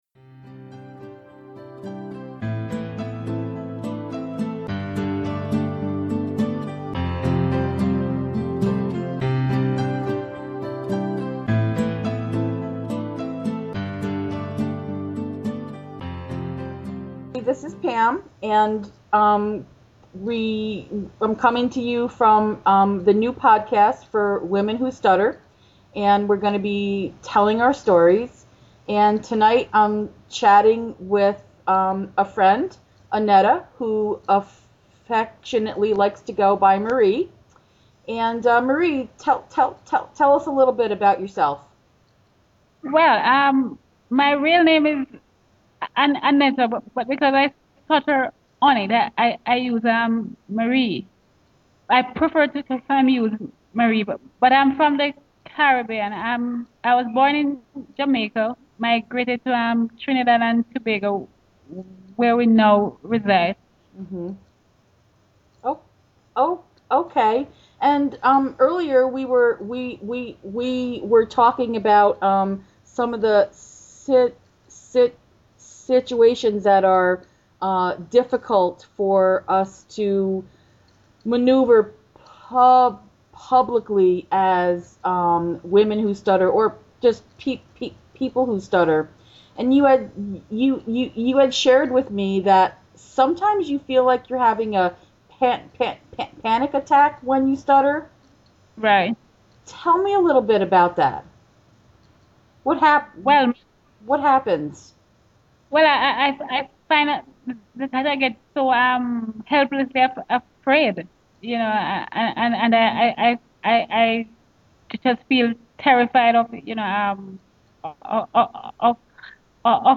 This episode features my first attempt at adding a little intro music. It took me a long time to add a 15 second clip.